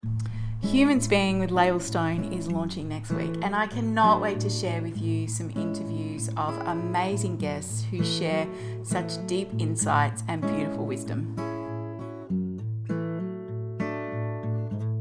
• Recorded at WeMOV, Melbourne